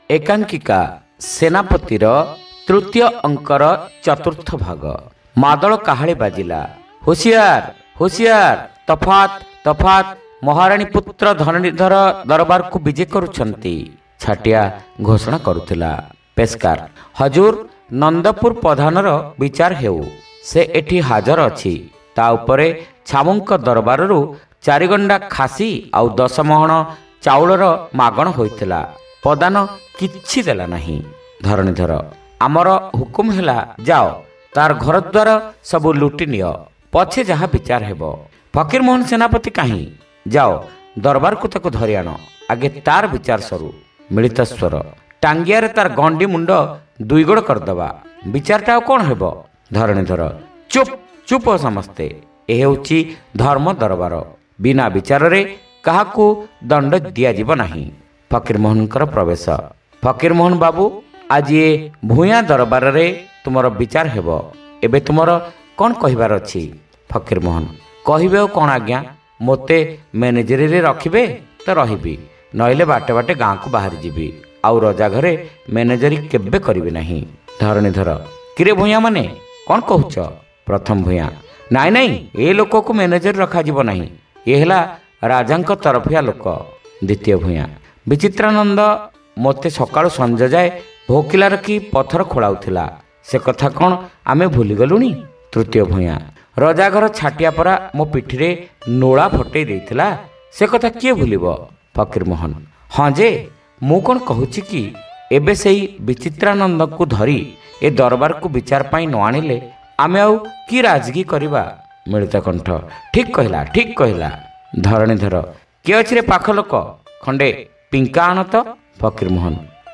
ଶ୍ରାବ୍ୟ ଏକାଙ୍କିକା : ସେନାପତି (ଷଷ୍ଠ ଭାଗ)